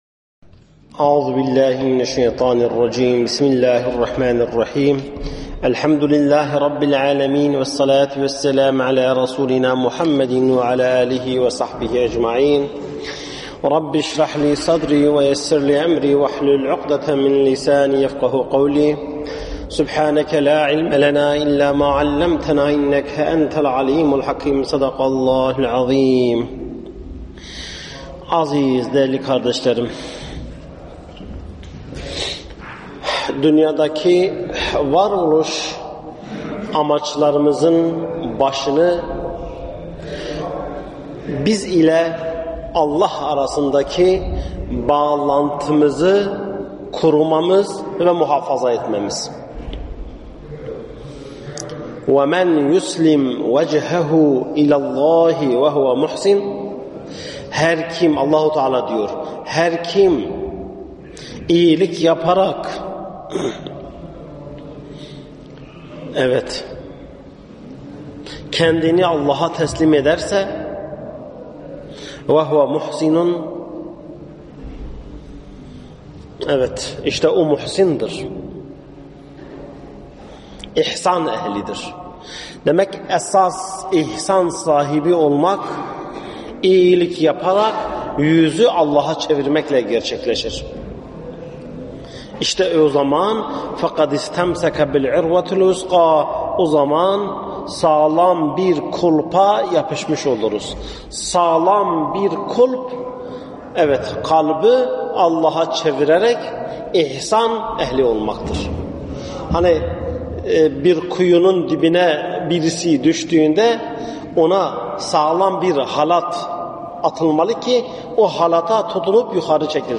SESLİ SOHBETLER